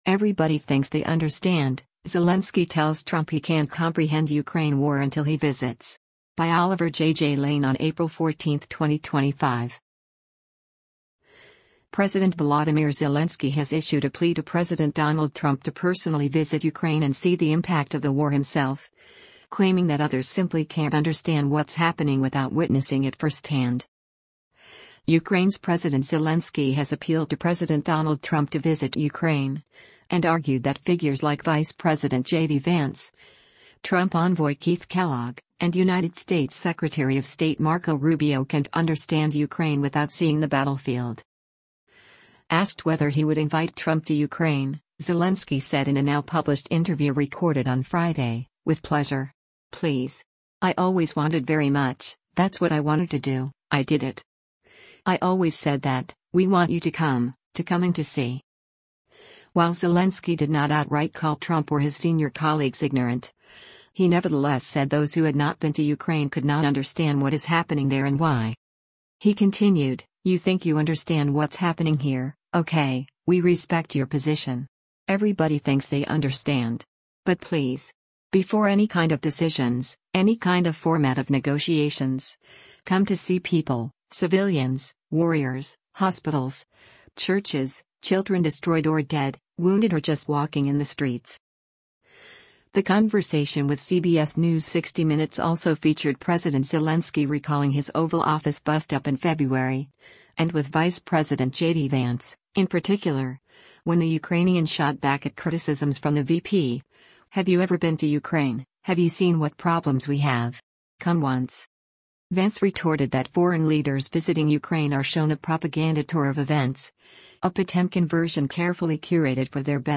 Ukrainian President Volodymyr Zelenskyy speaks during a press conference in Kyiv, Ukraine,